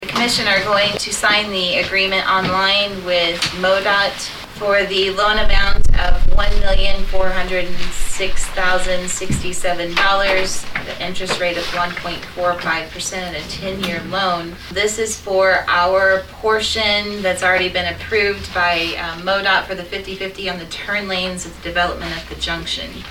During the meeting of the Saline County Commission on Thursday, January 7, Northern District Commissioner Stephanie Gooden gave the details about “Return to the Roost”- an event for Marshall High School graduates.